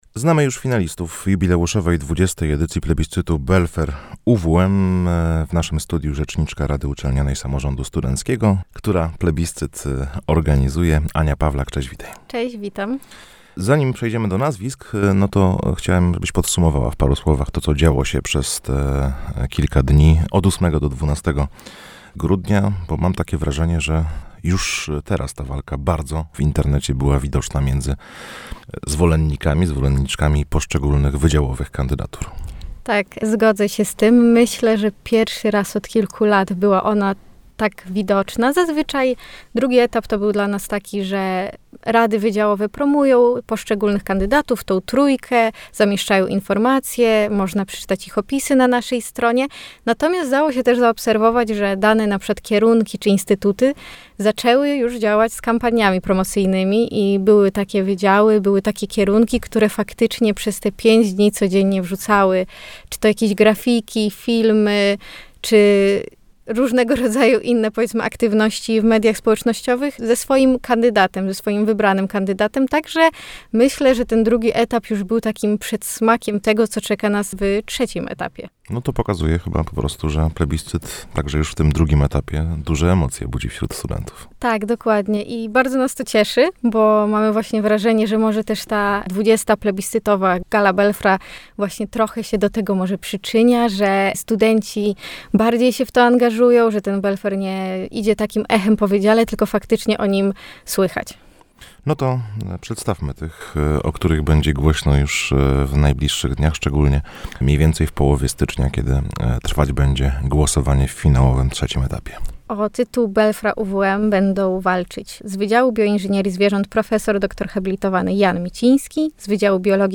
– tłumaczyła w naszym studiu